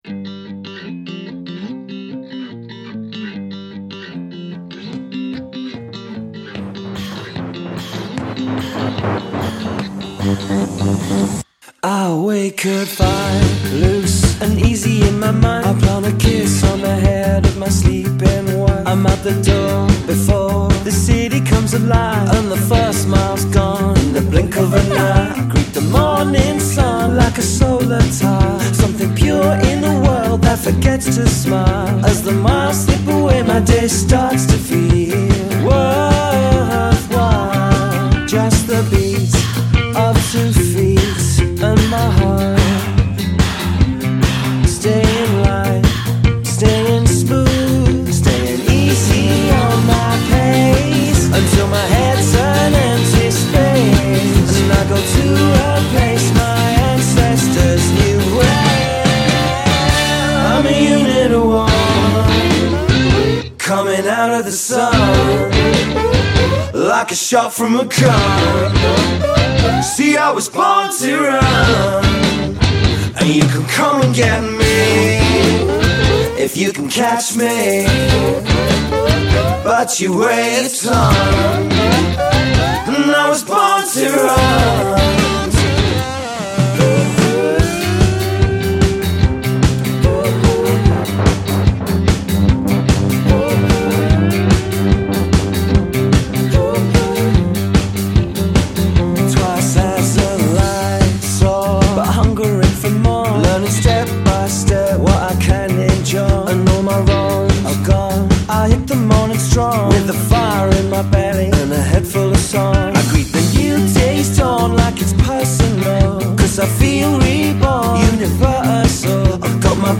Reading UK five piece
meld vintage influences and fresh grooves
Their heady brew of expansive indie pop and funky […]